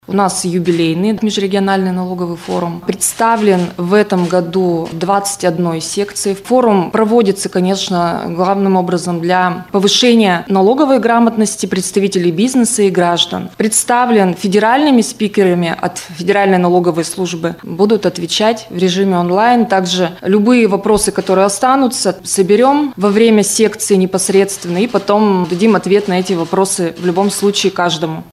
на пресс-конференции ТАСС-Урал.